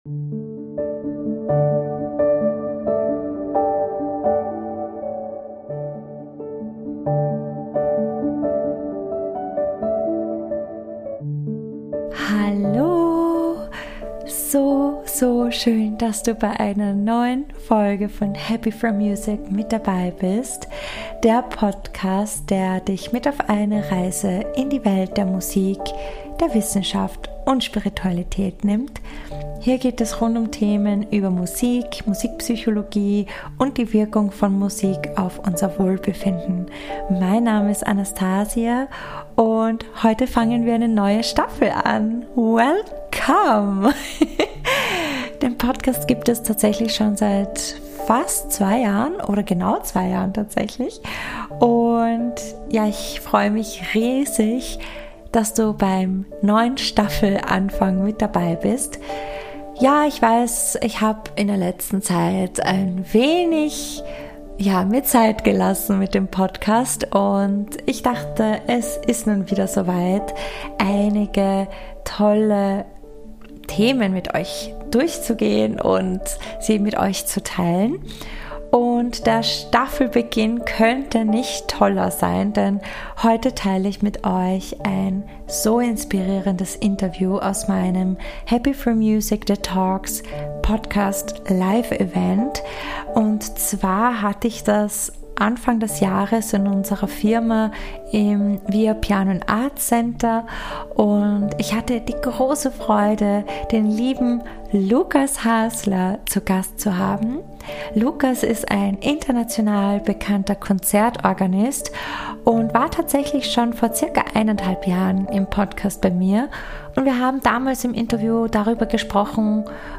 Interview Special